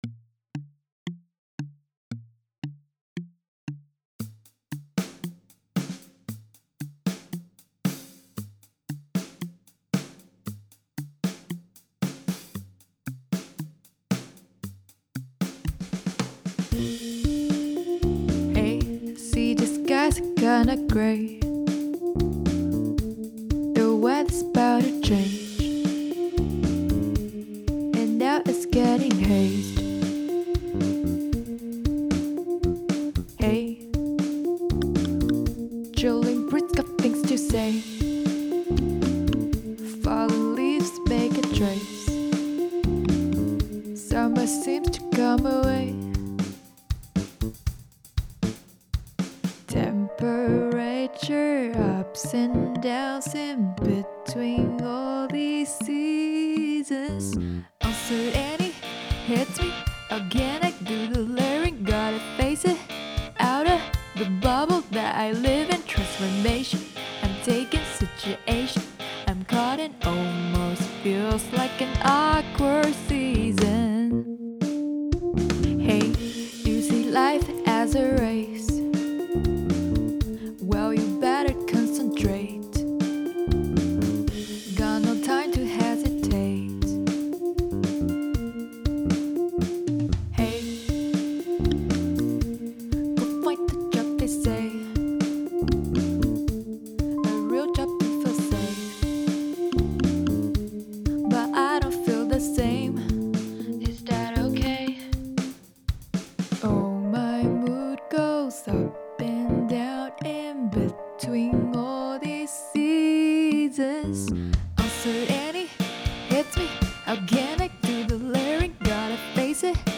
Genre: Alternative
Version: Demo